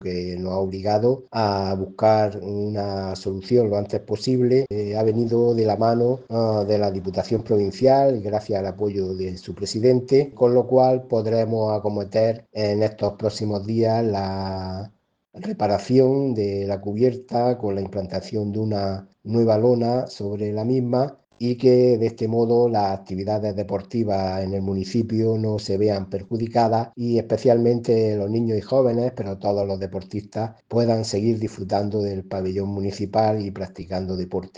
24-03-Audio-Alcalde-pabellon_deportes_dalias.mp3